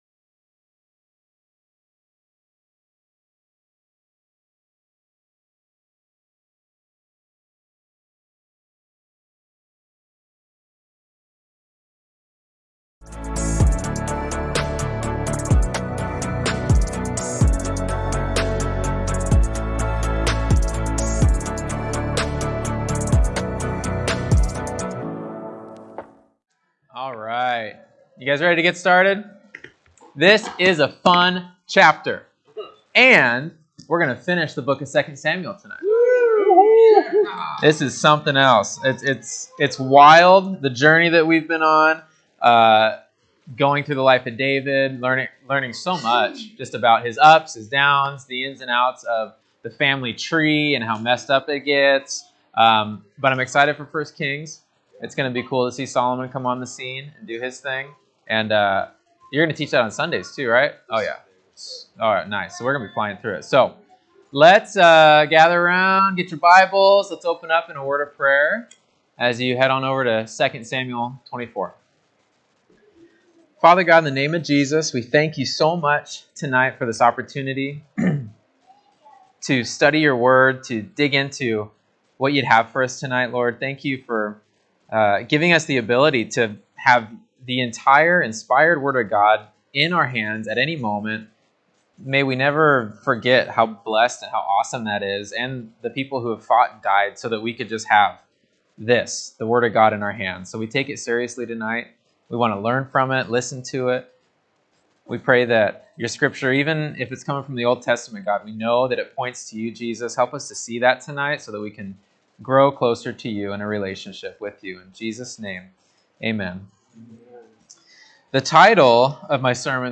Sermons Archive - Page 13 of 47 - Ark Bible Church